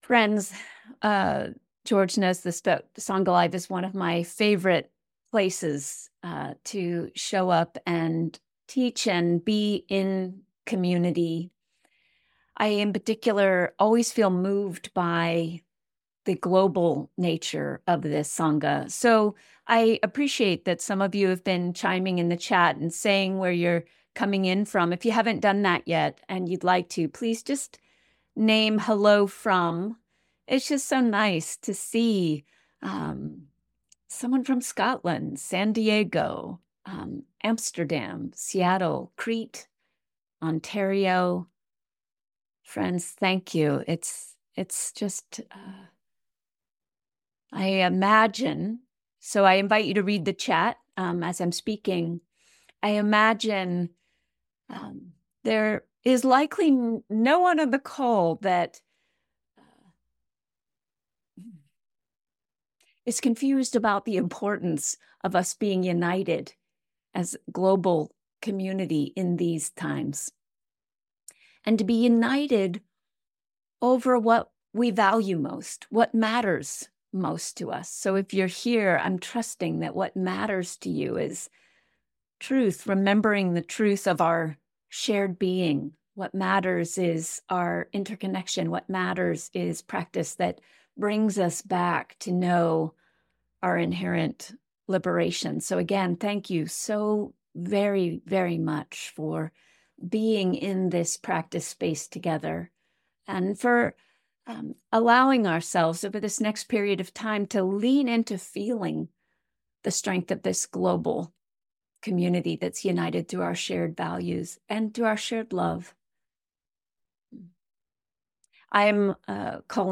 Who is it that suffers? And why is asking that question valuable in our spiritual practice? In this Sunday session, we’ll explore these questions, and more.